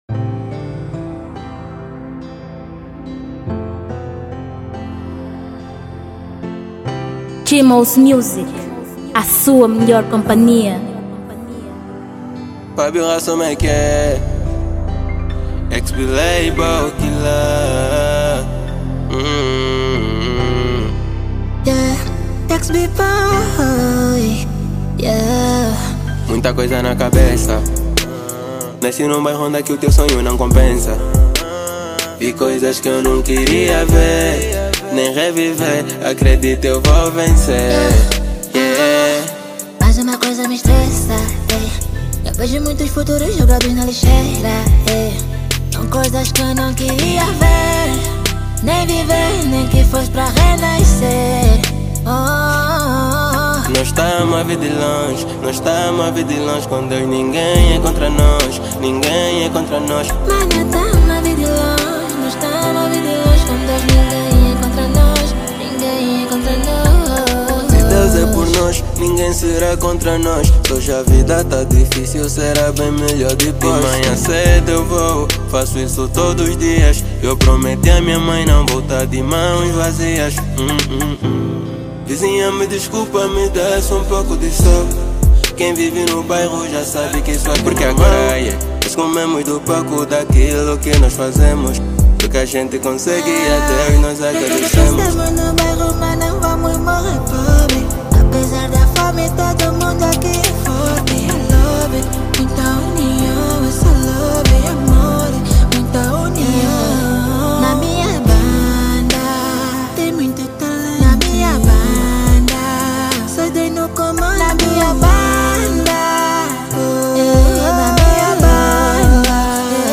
Gênero: Drill